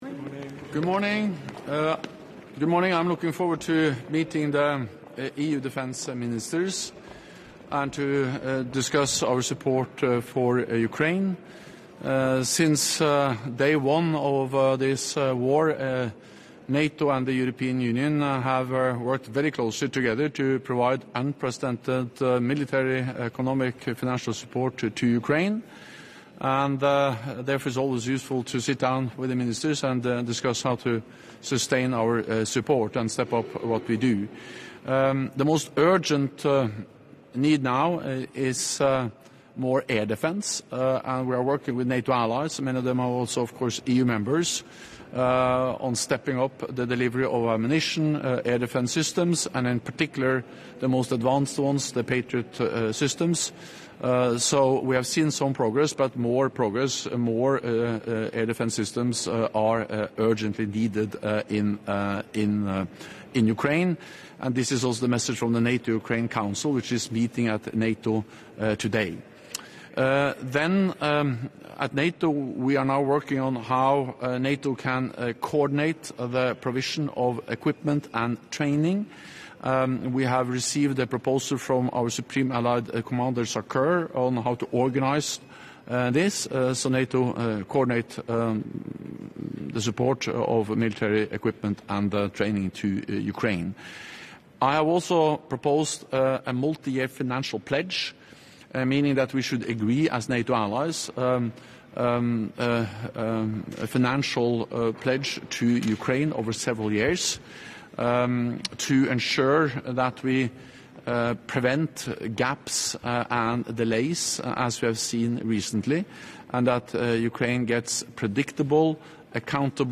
Doorstep statement
by NATO Secretary General Jens Stoltenberg ahead of the meeting of the Foreign Affairs Council of the European Union in Defence Ministers’ format